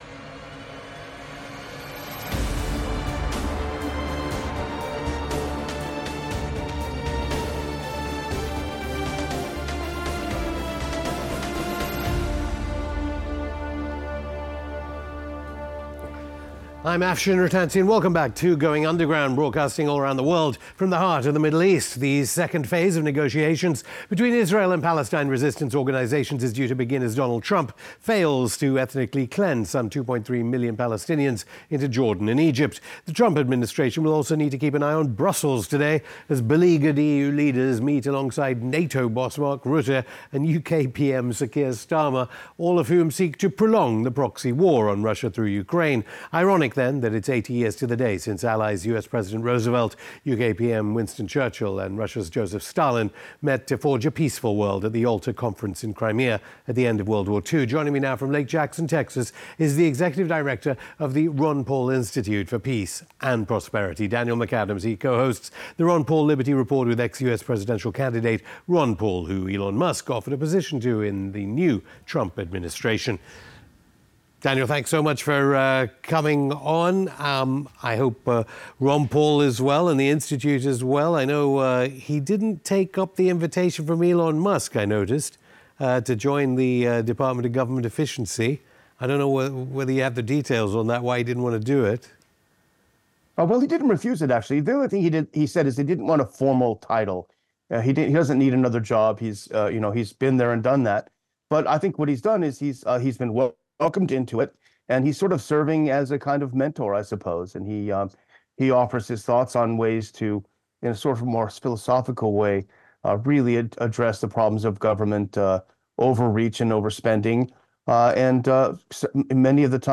Afshin Rattansi interviews